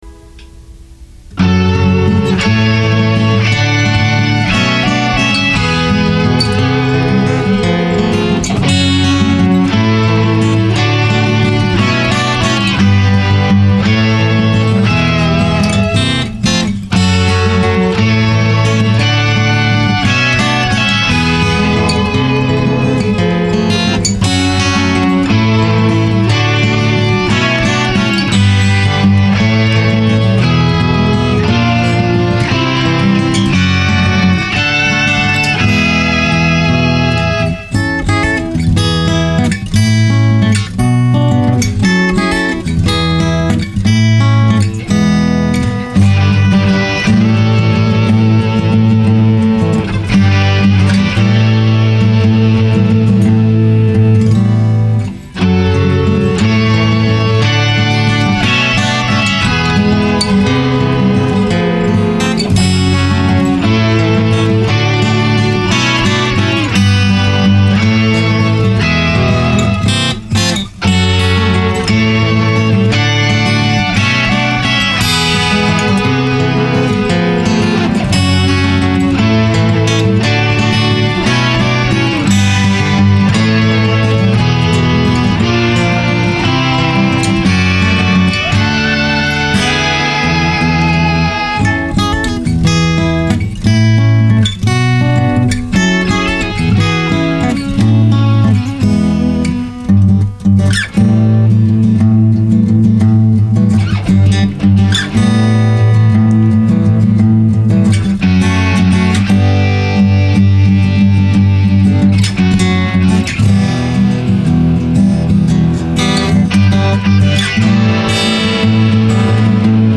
67 Telecaster guitar